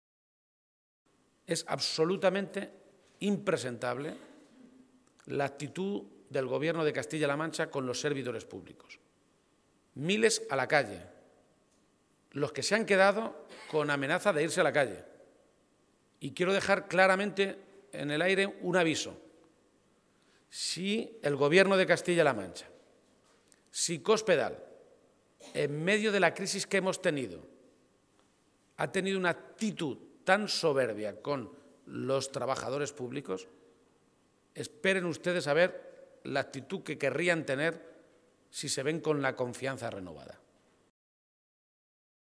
García-Page se pronunciaba de esta manera esta mañana, en una comparecencia, en Toledo, ante los medios de comunicación, en la que hacía balance municipal y regional del año que termina en unos días.
Cortes de audio de la rueda de prensa